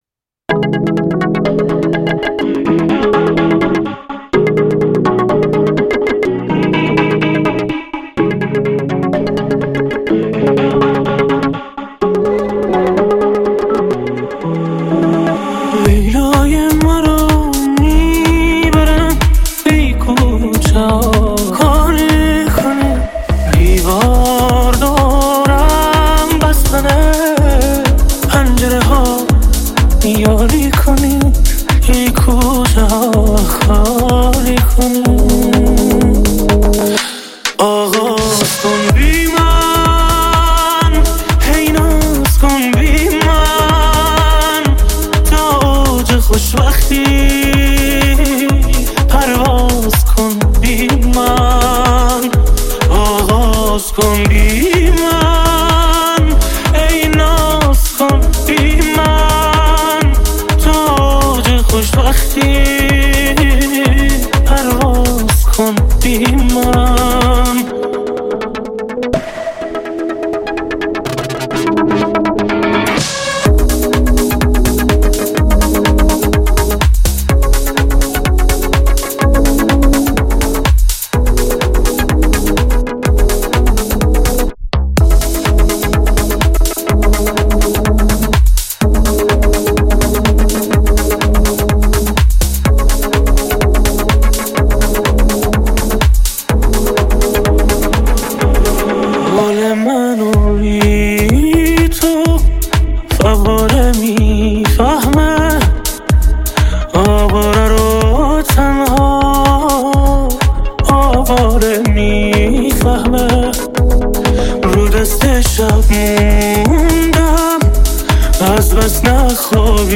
آهنگ ریمیکس